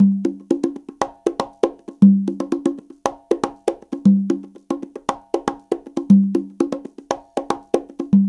CONGA BEAT38.wav